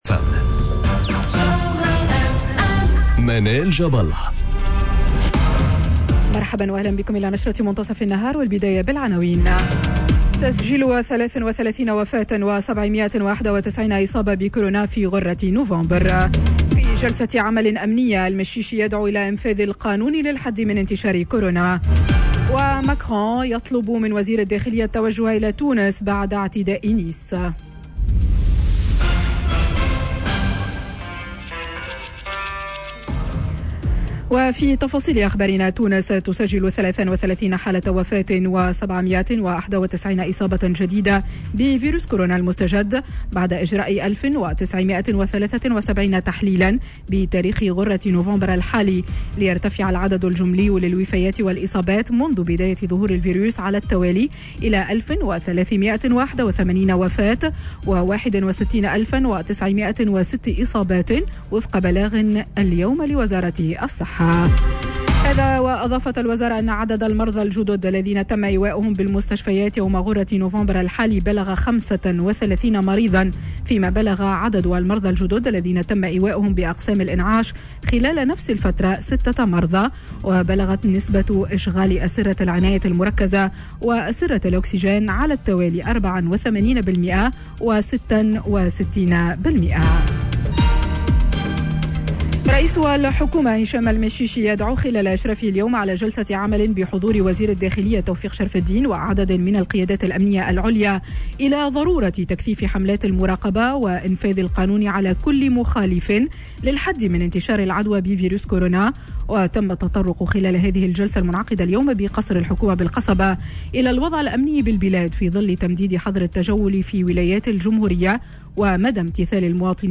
نشرة أخبار منتصف النهار ليوم الإثنين 02 نوفمبر 2020